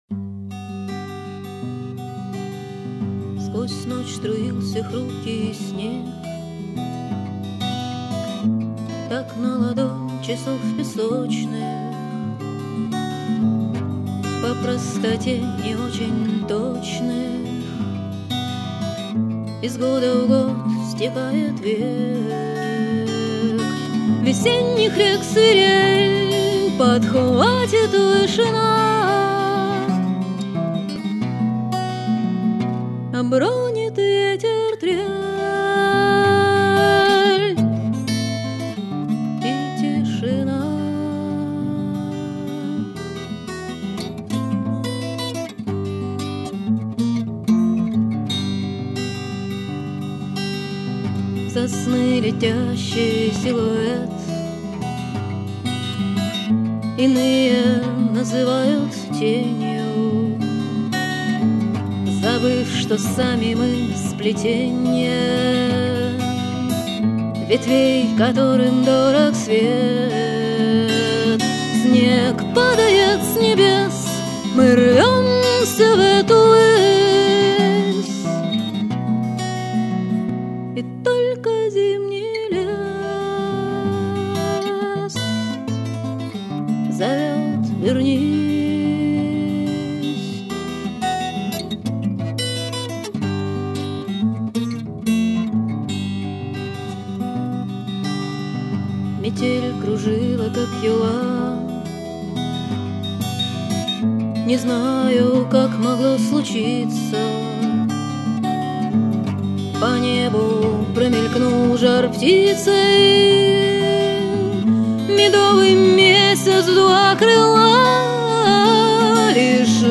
записанный в январе-августе 2000 года в домашней студии